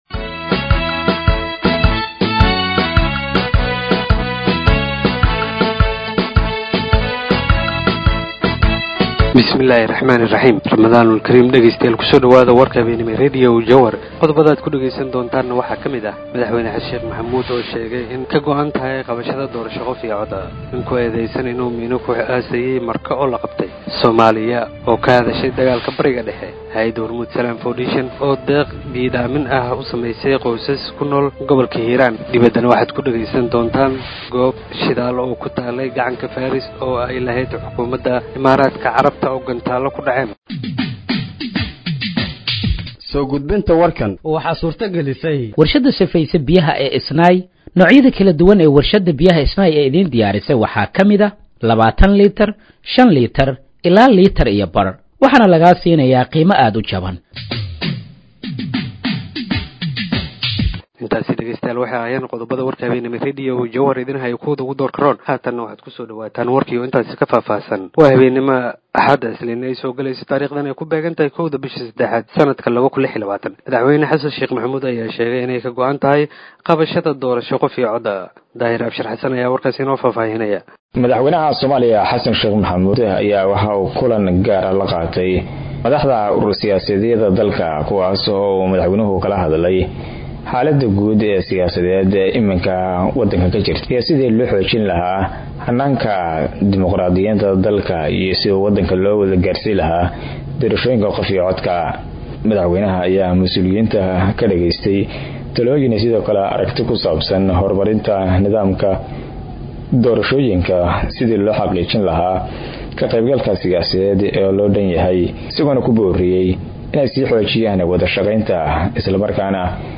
Warka Waxa aqrinaya Wariye